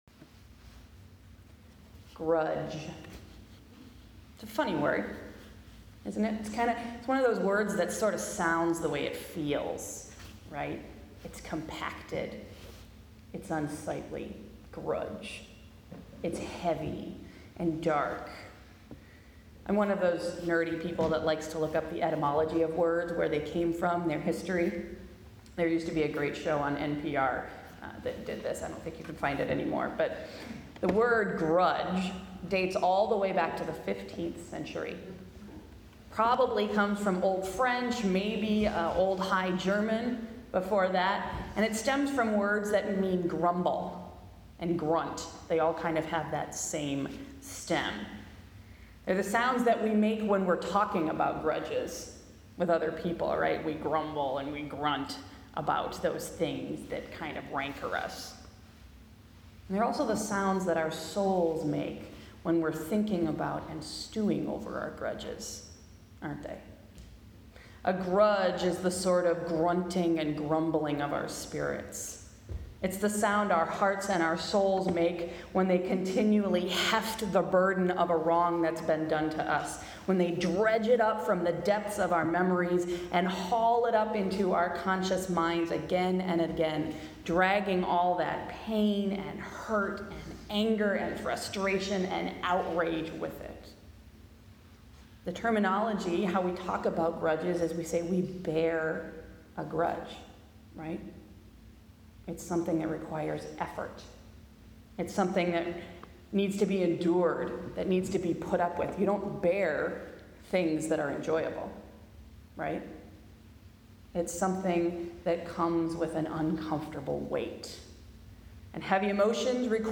Sunday’s sermon: It Ain’t Easy